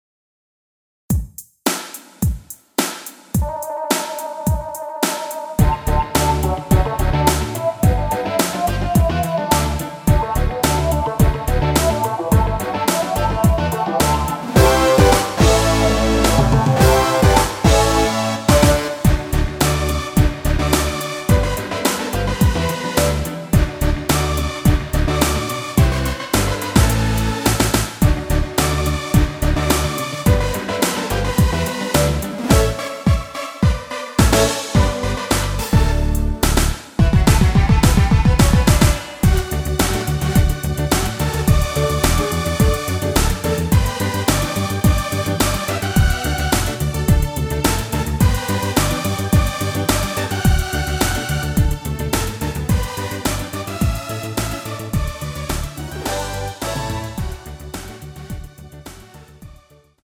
원키에서(-2)내린 멜로디 포함된 MR입니다.
앞부분30초, 뒷부분30초씩 편집해서 올려 드리고 있습니다.
중간에 음이 끈어지고 다시 나오는 이유는